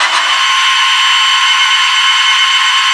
rr3-assets/files/.depot/audio/sfx/transmission_whine/tw_onmid.wav